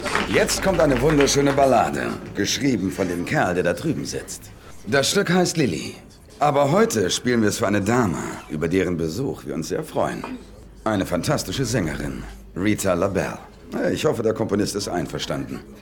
Cinephon, Berlin 2012
bandleader.mp3